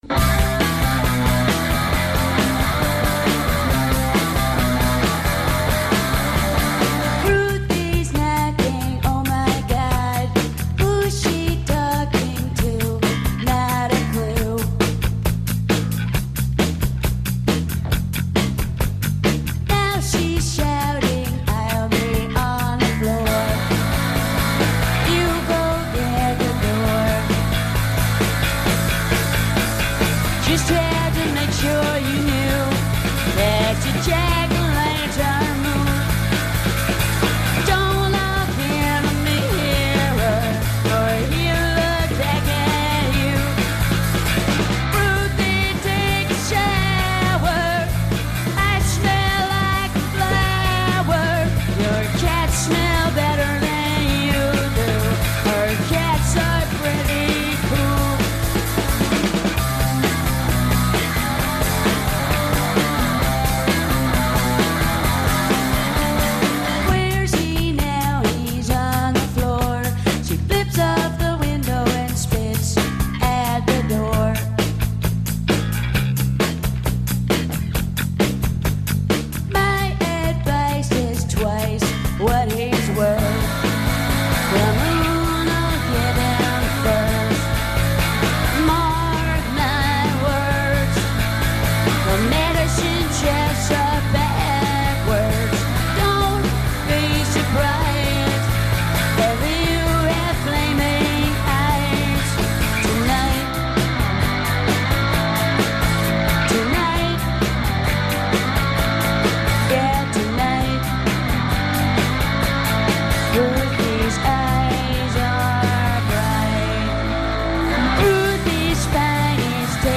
unorthodox and surreal